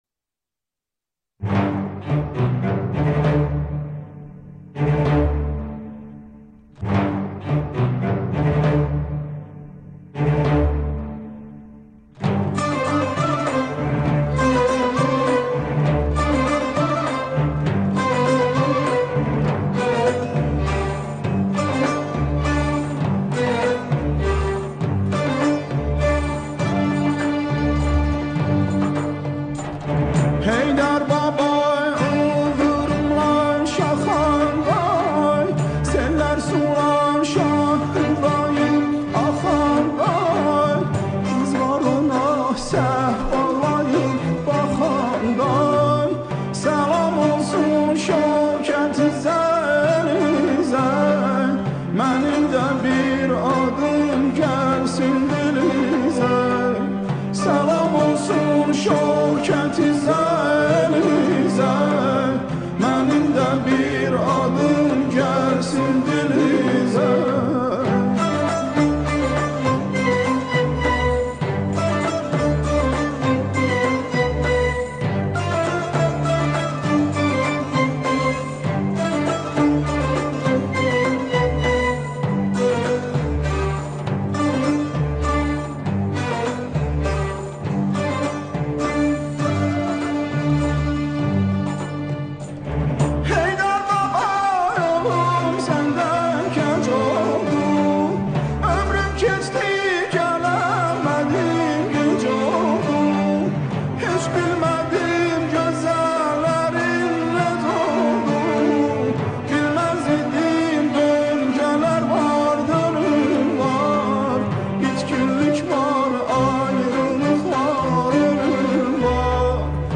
تک نوازی تار
با همراهی ارکستر